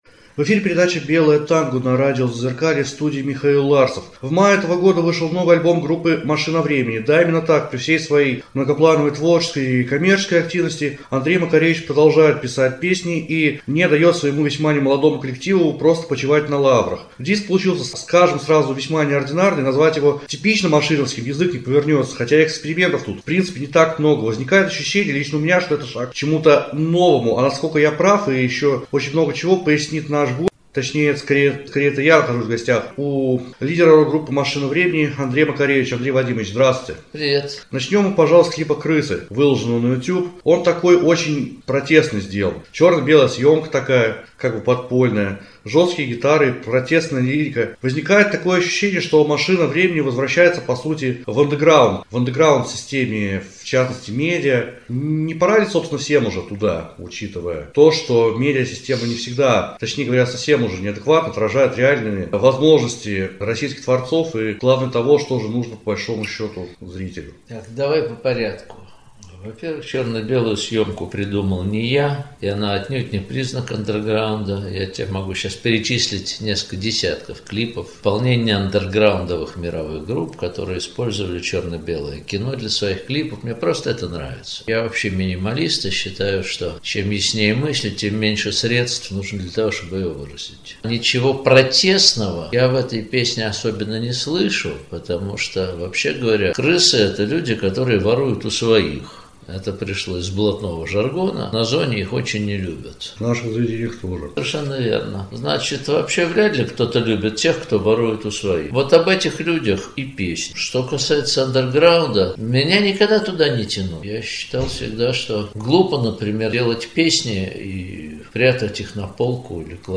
Интервью с Андреем Макаревичем